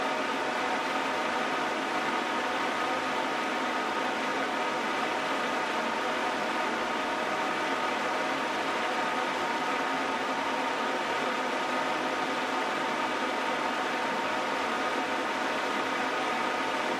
At mid to high speeds, noise increases in the middle frequencies (1 – 2 KHz), which can be annoying.
I have recorded the signals shown above, but please keep in mind that I’ve enabled Automatic Gain Control (AGC) to do so to make it easier for you to reproduce them.
100% Fan Speed